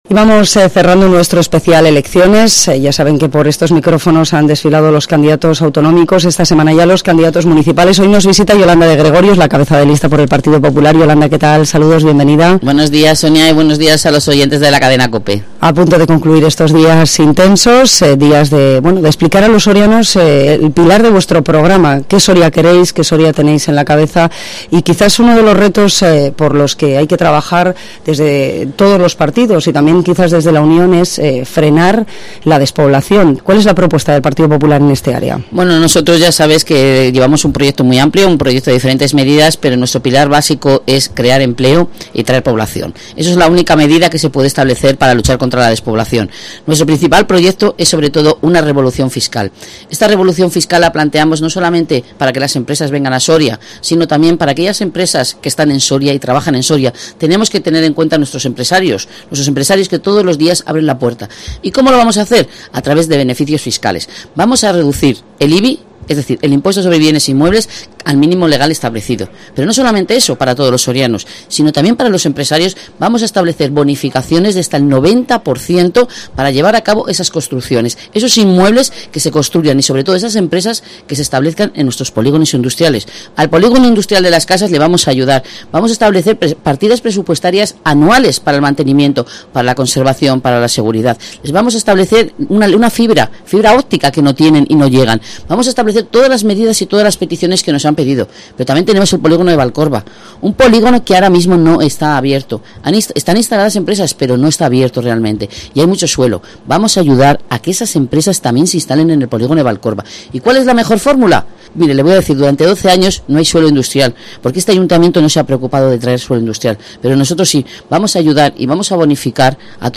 Entrevista Partido Popular elecciones municipales Soria